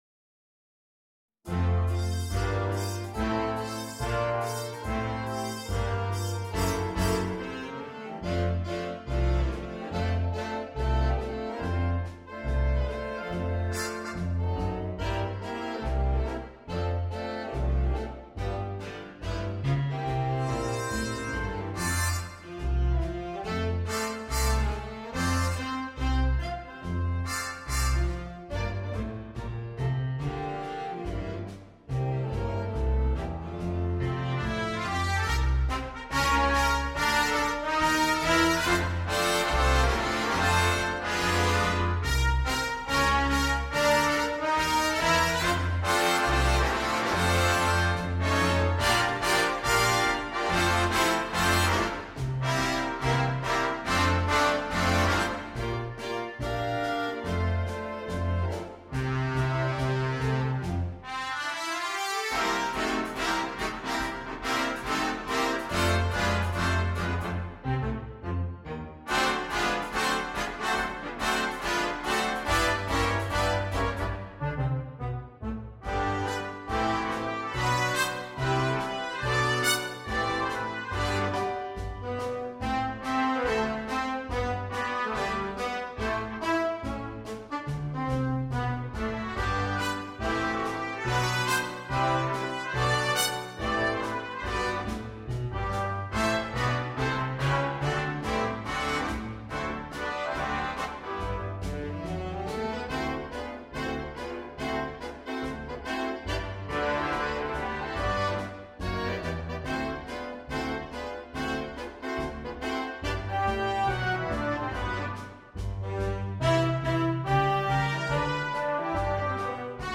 на биг-бэнд.